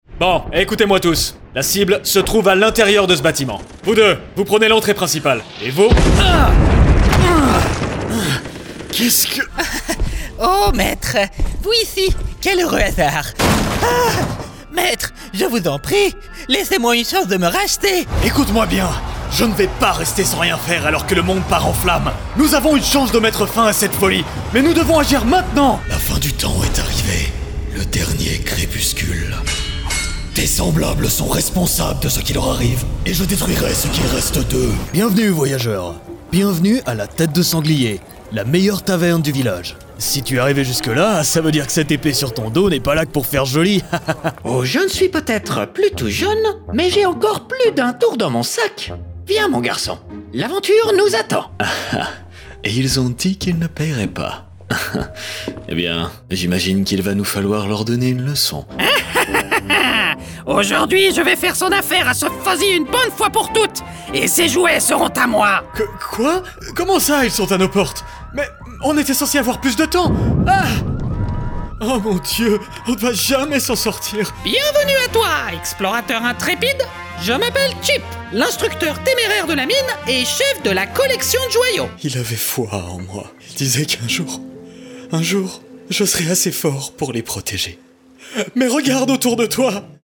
Voice actor since 2016 - French + French-accented & International English
Character Reel
French from France / International English / French-accented English
Young Adult
Middle Aged